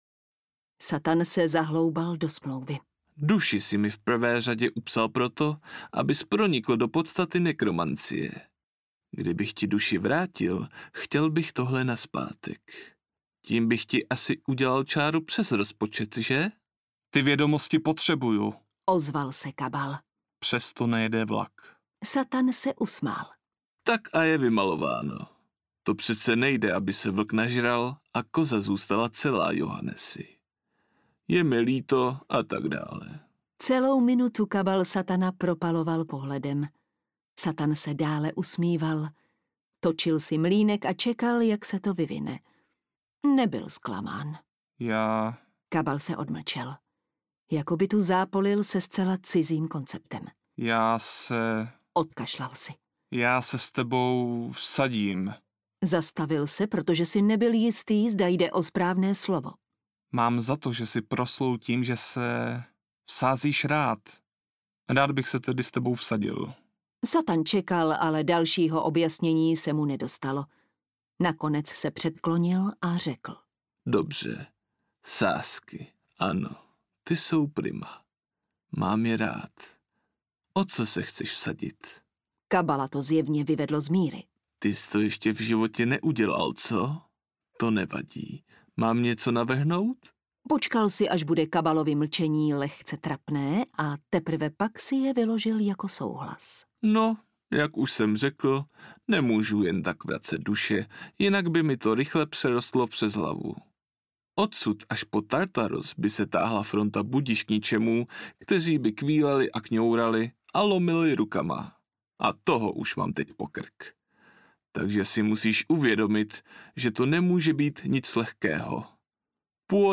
Nekromant Johannes Cabal audiokniha
Ukázka z knihy